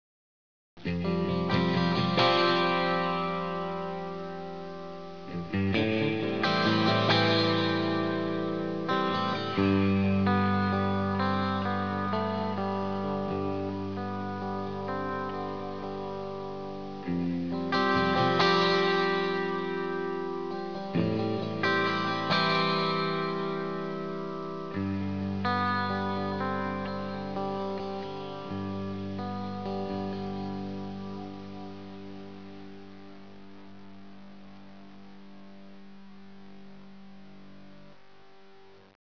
ESEMPIO AUDIO 03 - REVERB
Reverb Plate Tonex
03-REVERB-PLATE-2.wav